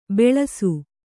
♪ beḷasu